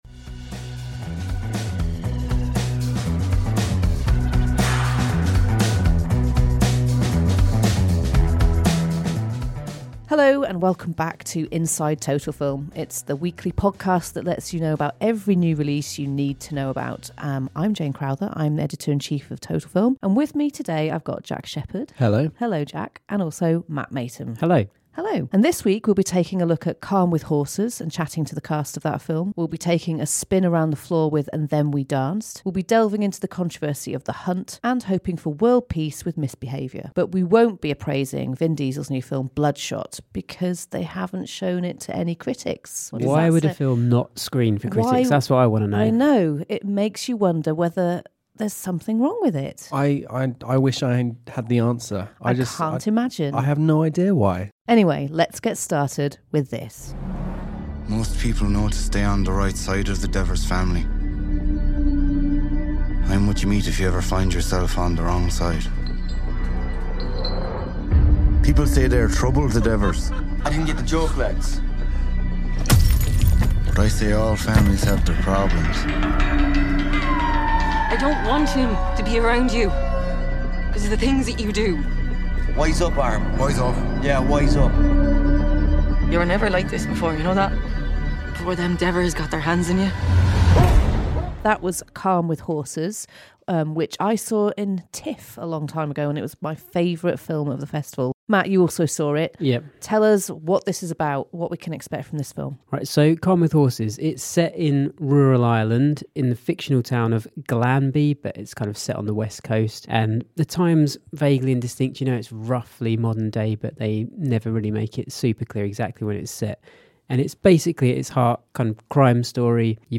Interview with the film’s stars Cosmo Jarvis and Barry Keoghan